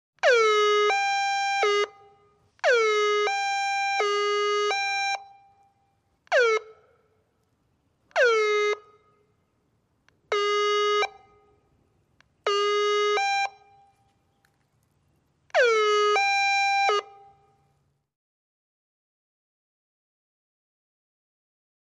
High-low Siren, Short Bursts Close.